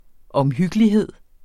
Udtale [ ʌmˈhygəliˌheðˀ ]